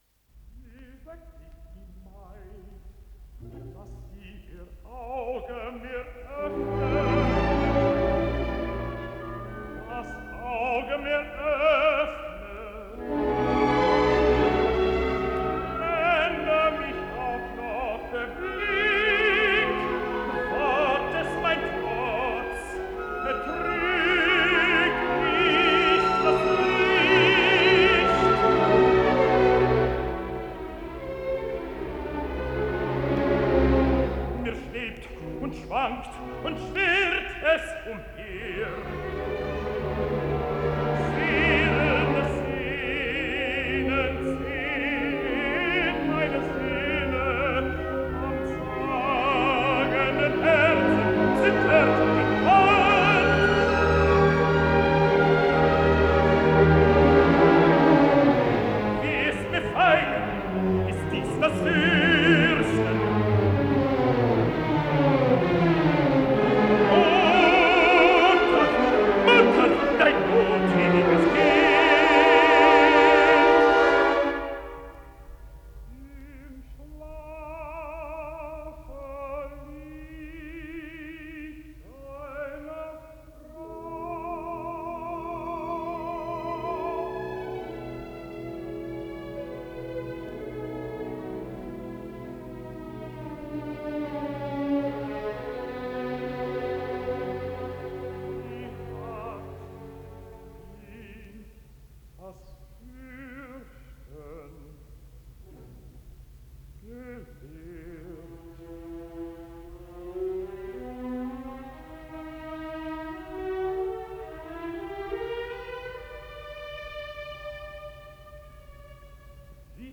Исполнитель: Солисты и оркестр Берлинской филармонии
Формуляр Название передачи Опера Зигфрид Подзаголовок В трех действиях на немецком языке Код ПКС-07728 Фонд Без фонда (ГДРЗ) Редакция Музыкальная Общее звучание 03:59:40 Дата добавления 13.10.2024 Прослушать